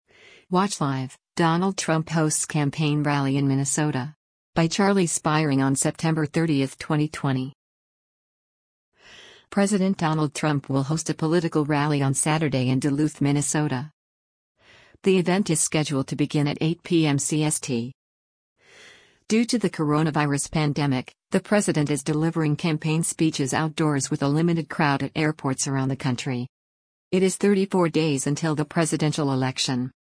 Watch Live: Donald Trump Hosts Campaign Rally in Minnesota
Due to the coronavirus pandemic, the president is delivering campaign speeches outdoors with a limited crowd at airports around the country.